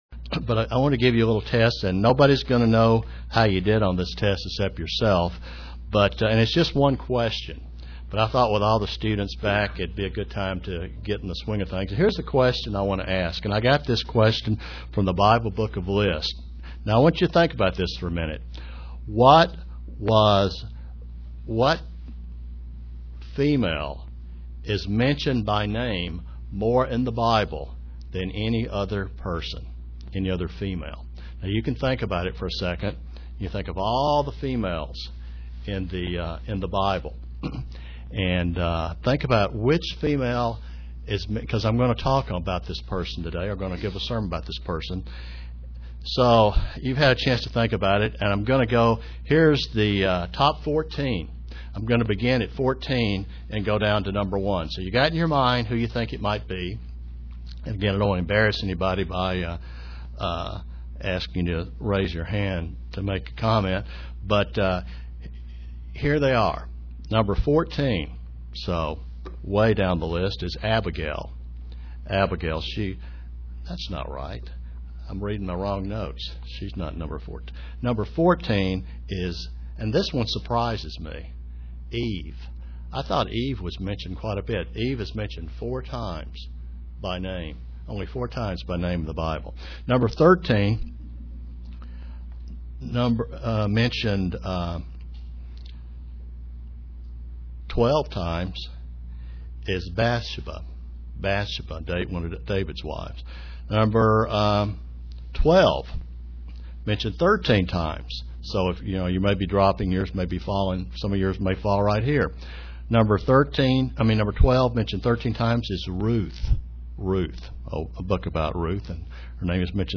Sermons
Given in Kingsport, TN London, KY